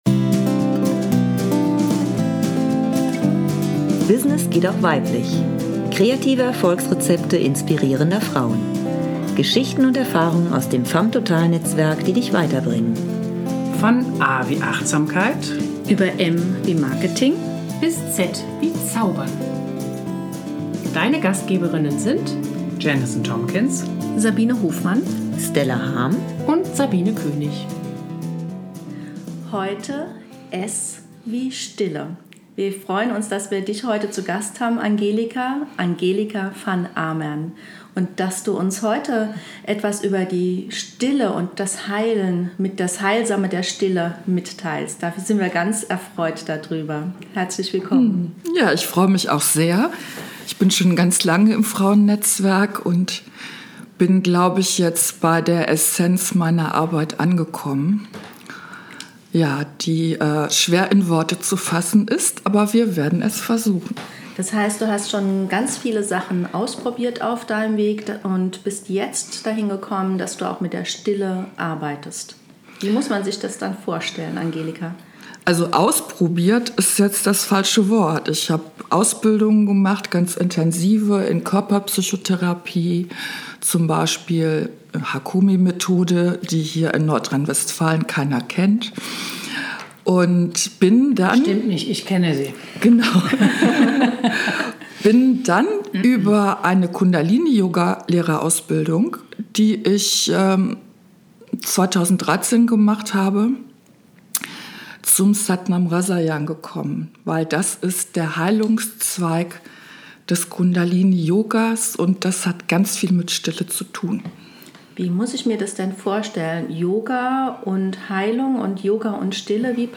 Sie und viele spannenden Gästinnen begleiten dich mit Freude und großem Elan durch viele spannende Interviews mit den unterschiedlichsten Themen.